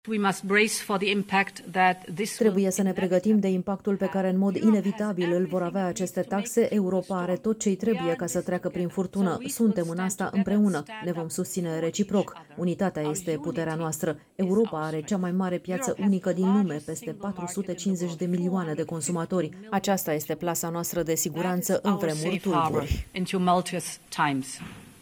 03apr-08-Ursula-trebuie-sa-ne-pregatim-de-impact-tradus.mp3